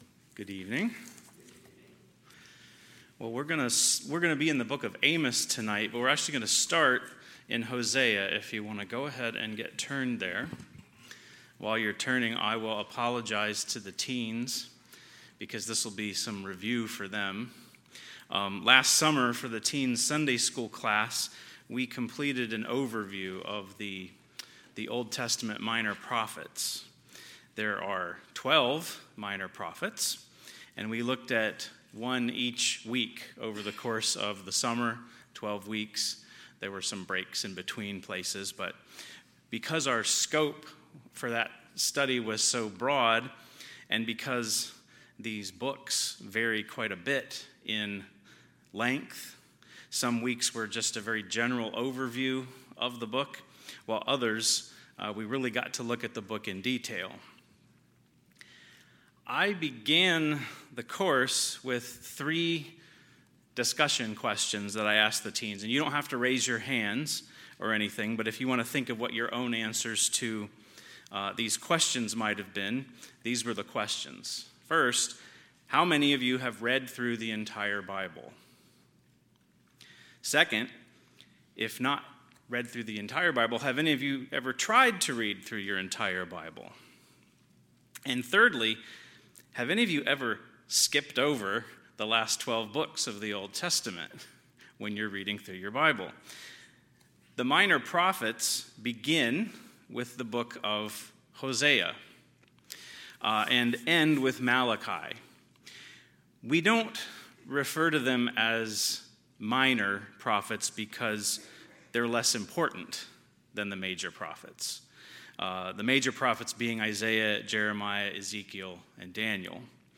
Sunday, December 14, 2025 – Sunday PM
Sermons